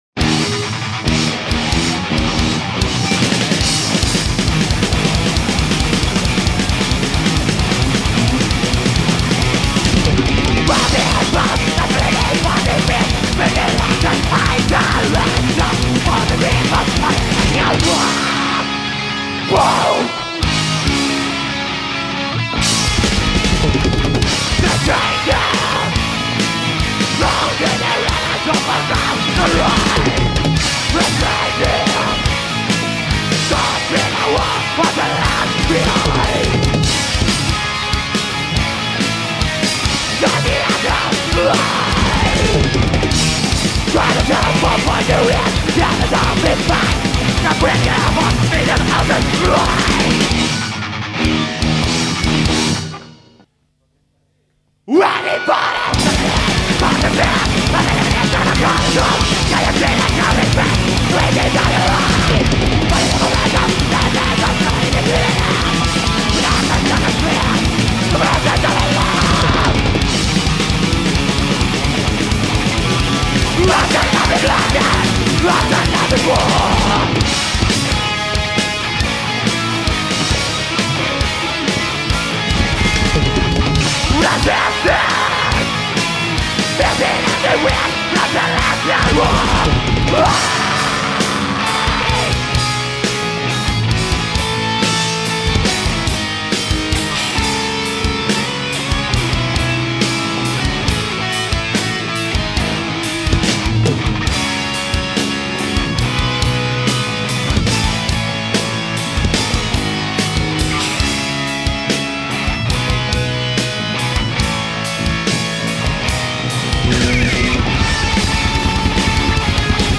Registrazioni dal vivo
3'27" 3,17MB Comunità Giovanile